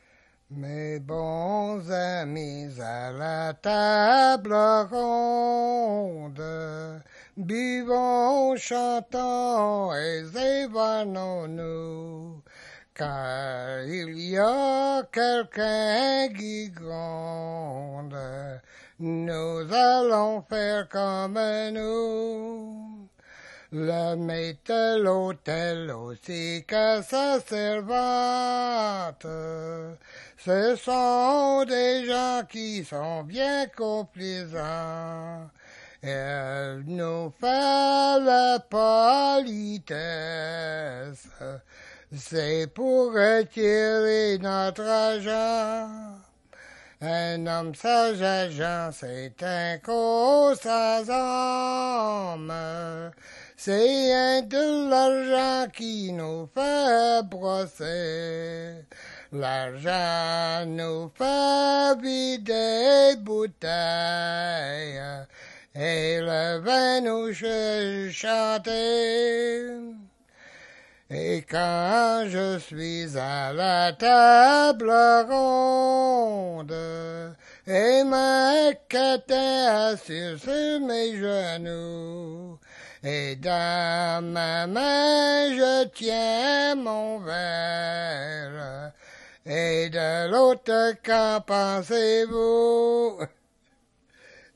Chanson Item Type Metadata
Emplacement Lourdes-de-Blanc-Sablon